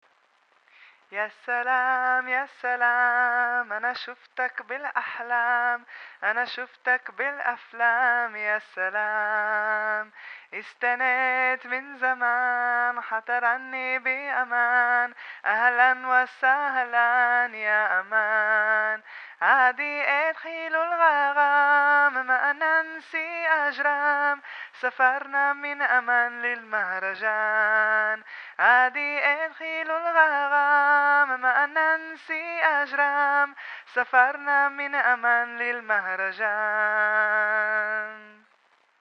The short stop became a song composition, live concert and a recording -